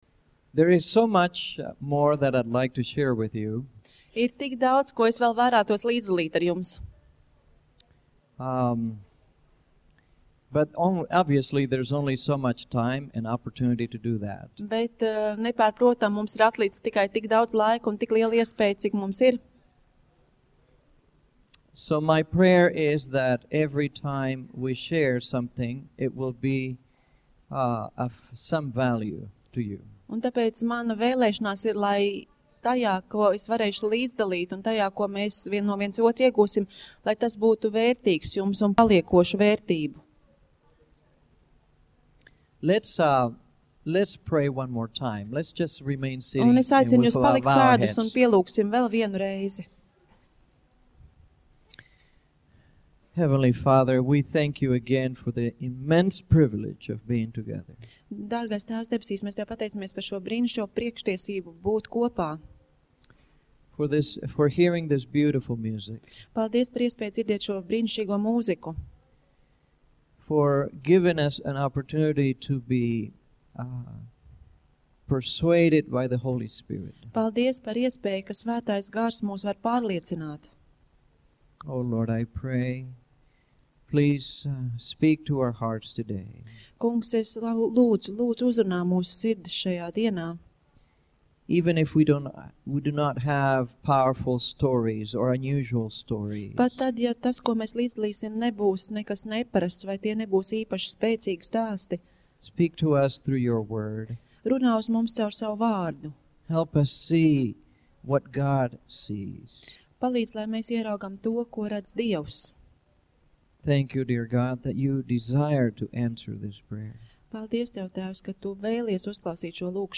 Seminārs